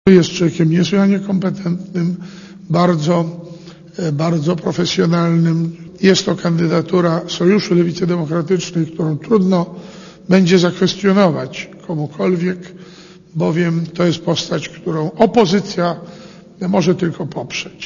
mówi Jóżef Oleksy